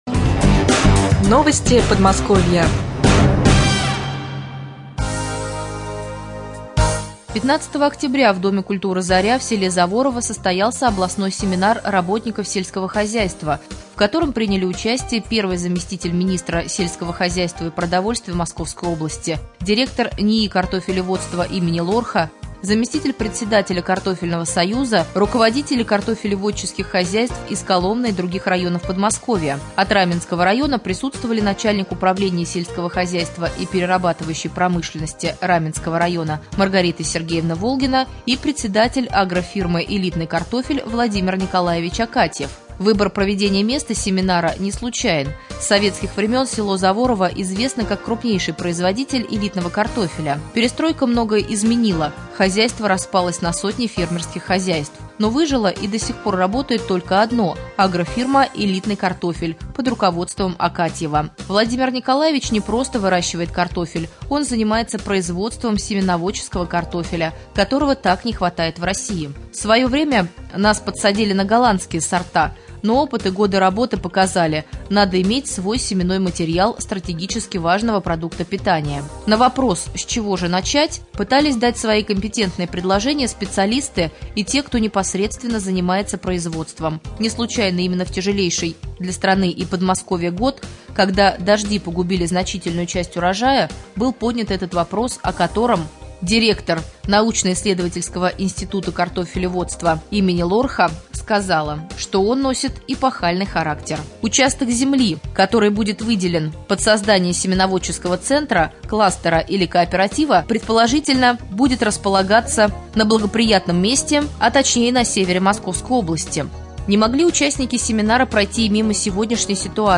24.10.2013г. в эфире раменского радио - РамМедиа - Раменский муниципальный округ - Раменское